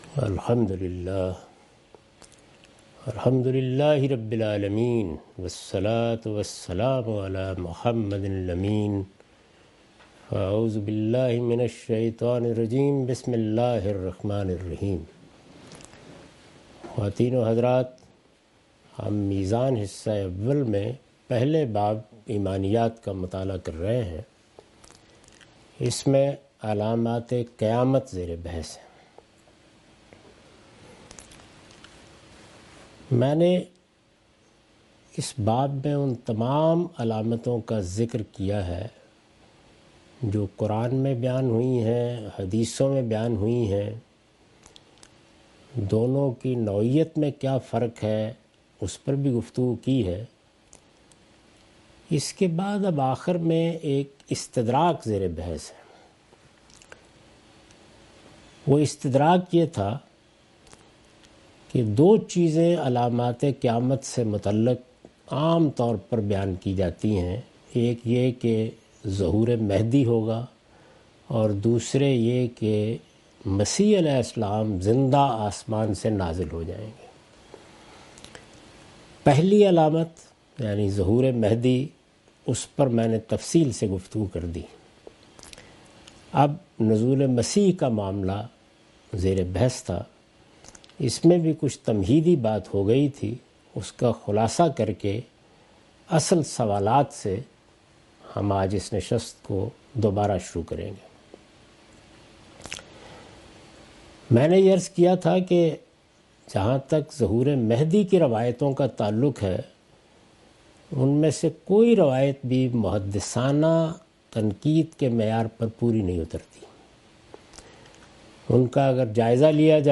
Meezan Class by Javed Ahmad Ghamidi.